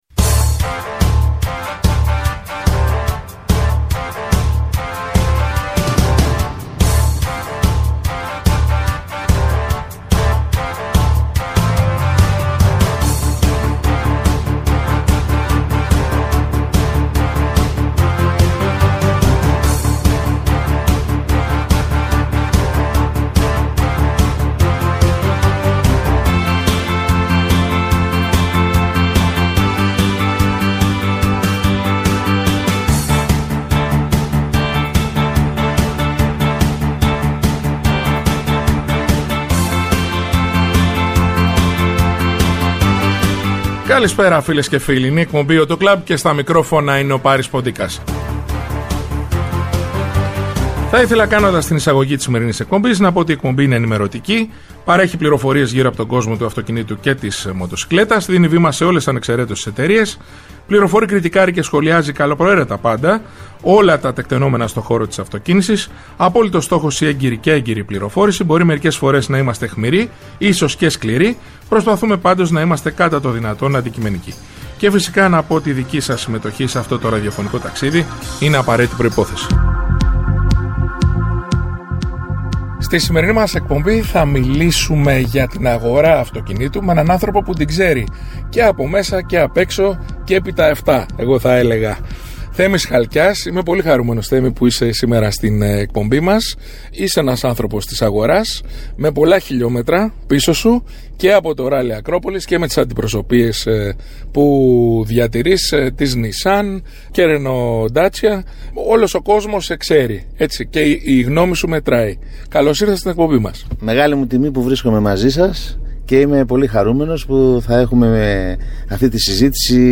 Η εκπομπή «AUTO CLUB» είναι ενημερωτική, παρέχει πληροφορίες γύρω από τον κόσμο του αυτοκινήτου και της μοτοσικλέτας, δίνει βήμα σε ολες ανεξεραίτως τις εταιρείες, φιλοξενεί στο στούντιο ή τηλεφωνικά στελέχη της αγοράς, δημοσιογράφους αλλά και ανθρώπους του χώρου. Κριτικάρει και σχολιάζει καλοπροαίρετα πάντα όλα τα τεκτενόμενα στο χώρο της αυτοκίνησης, με απόλυτο στόχο την έγκαιρη και έγκυρη πληροφόρηση για τους ακροατές, με «όπλο» την καλή μουσική και το χιούμορ.